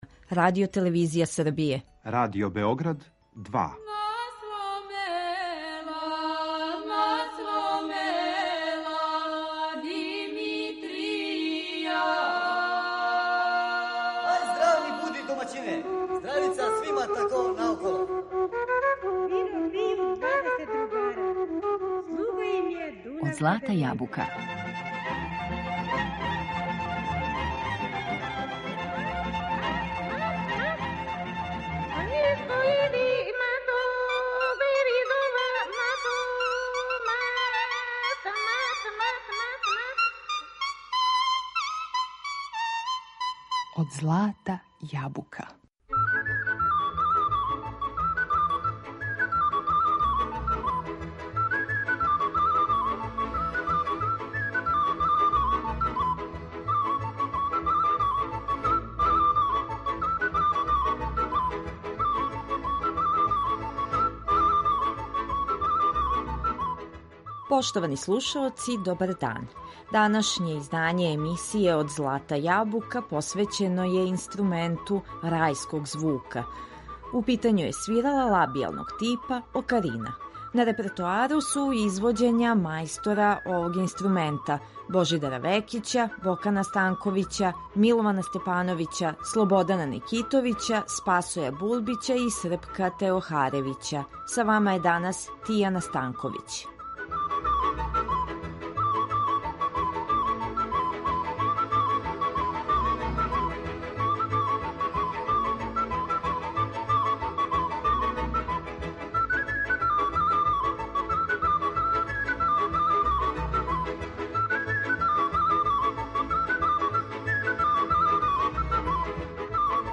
Окарина
Данашње издање емисије Од злата јабука посвећено је свирали лабијалног типа – окарини.